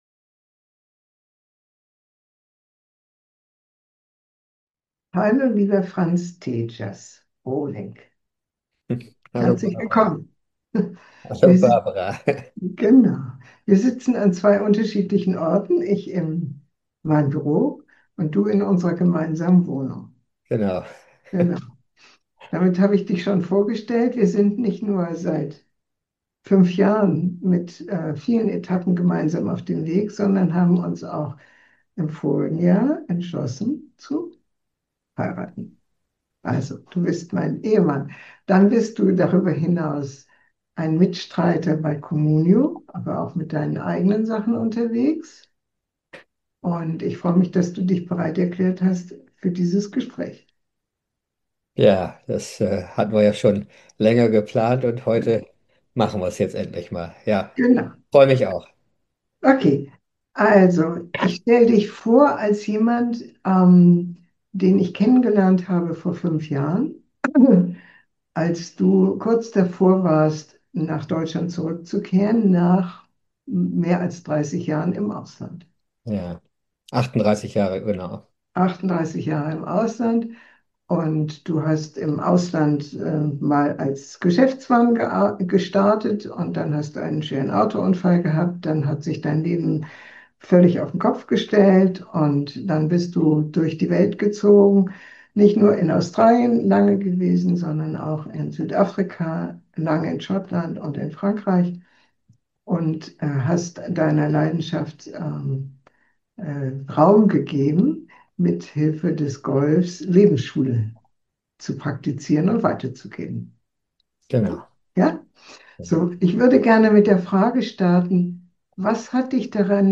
Interview mit Dipl.